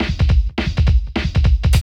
14 LP FILL-R.wav